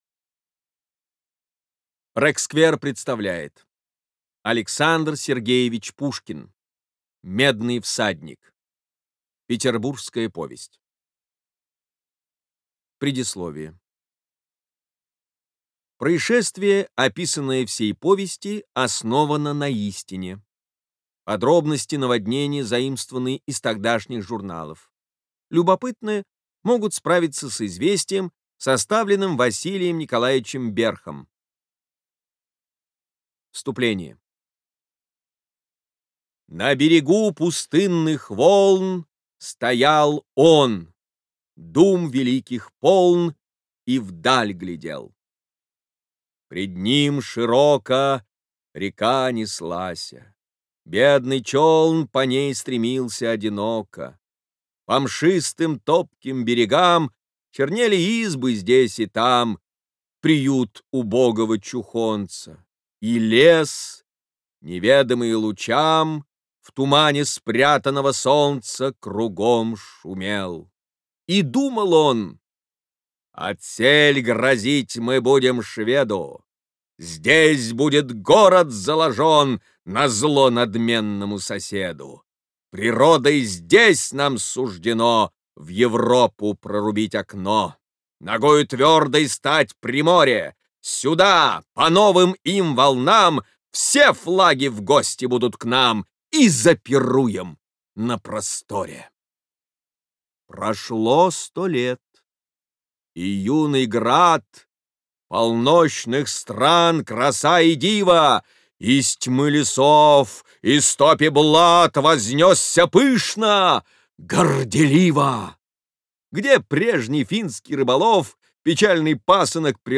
Бесплатная аудиокнига «Медный Всадник» от Рексквер.
Классическую литературу в озвучке «Рексквер» легко слушать и понимать благодаря профессиональной актерской игре и качественному звуку.